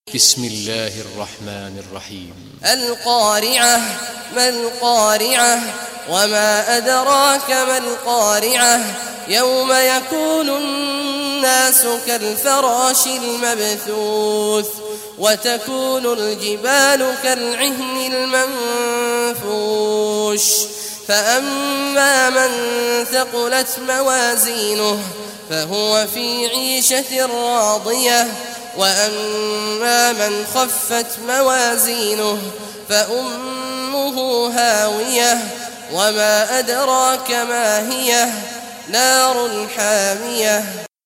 Surah Qariah Recitation by Sheikh Awad al Juhany
Surah Qariah, listen or play online mp3 tilawat / recitation in Arabic in the beautiful voice of Sheikh Abdullah Awad Al Juhany.